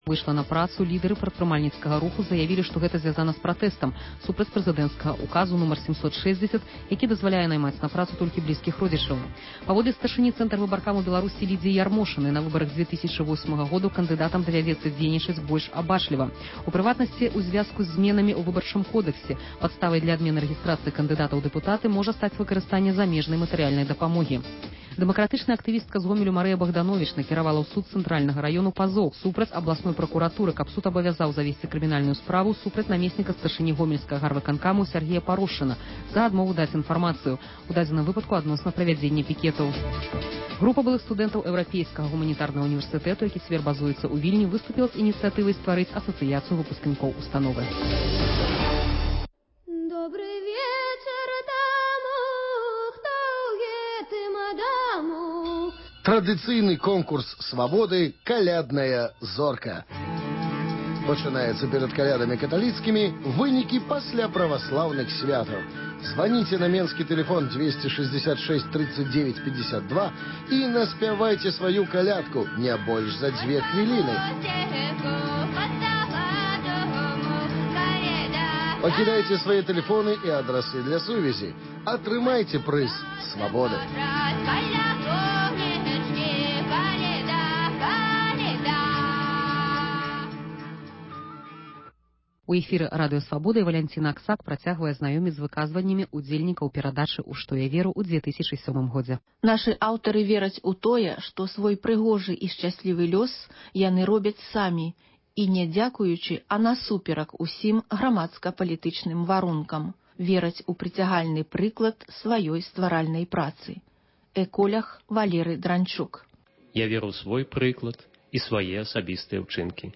Званкі на “Свабоду”: народ пра Лукашэнку і чыноўнікаў.